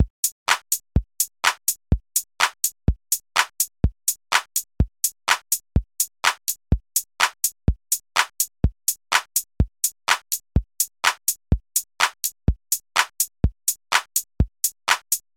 A conventional drumtrack might involve a bass drum, high-hat, and hand clap, which may involve MIDI pitch 36, 44, and 39. However 44 was pretty inaudible on the SoundFonts I tried, so I switched that to 42.